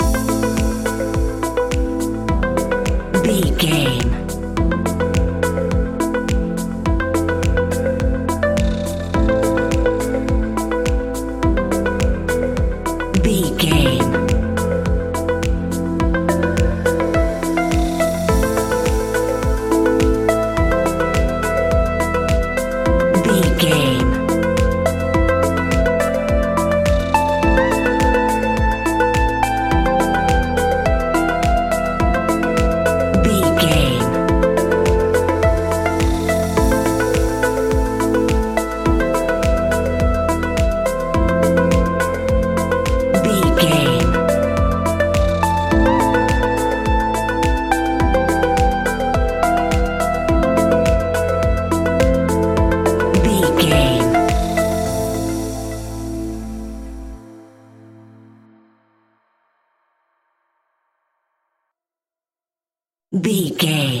Evening Tropical House 60 Sec.
Aeolian/Minor
SEAMLESS LOOPING?
groovy
calm
smooth
dreamy
uplifting
piano
drums
drum machine
synthesiser
house
synth pop
synth bass